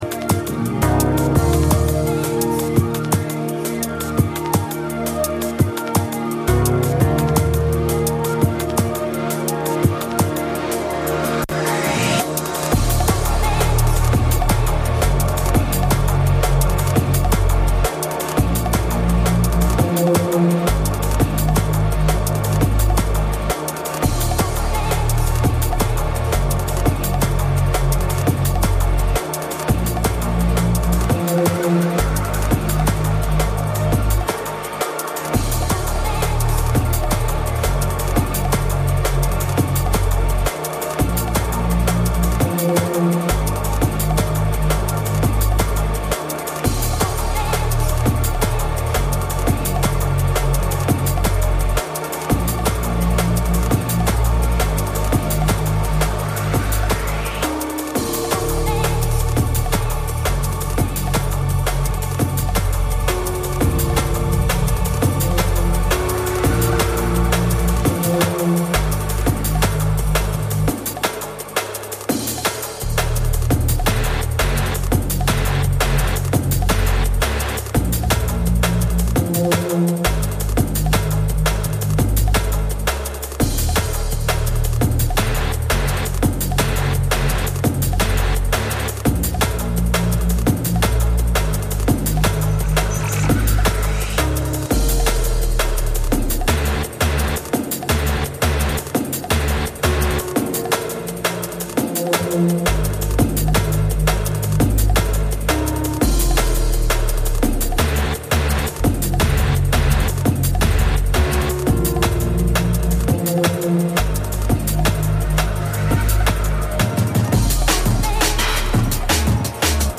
Experimental, Dub, Electronica, etc The Groove l�uft jeden zweiten Montag 23-01 Uhr und wird wechselnd am 1.
House, Drum�n�Bass, Breaks, Hip Hop, ...